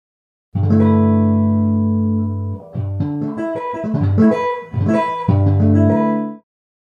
E' molto interessante anche sperimentare cosa vien fuori aggiungendo sempre lo stesso tipo di intervallo, soprattutto riferendosi a intervalli abbastanza ampi, ad esempio si può provare con l'intervallo di quinta giusta: a un G aggiungiamo sempre delle quinte e otterremo G, D, A, E, B [